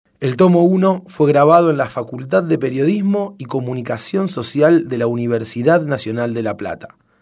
Palabras claves: Narración de cuentos ; Audiolibro